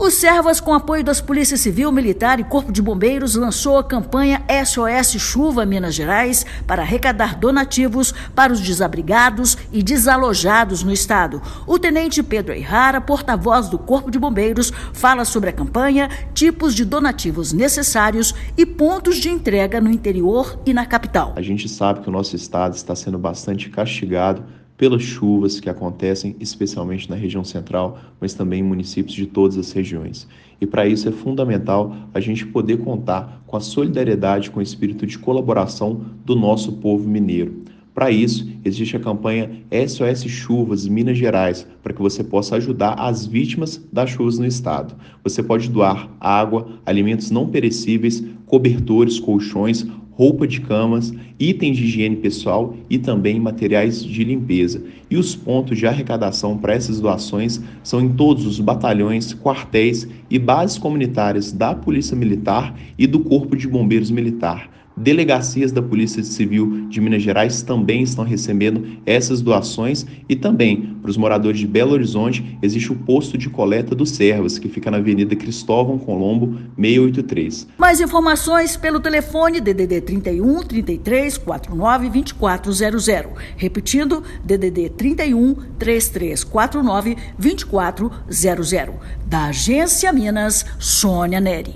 O Serviço Social Autônomo (Servas) , com apoio das polícias Civil, Militar e Corpo de Bombeiros, lançou a campanha “SOS Chuva Minas Gerais” para arrecadar donativos para os desabrigados e desalojados pelas chuvas no estado. Ouça matéria de rádio.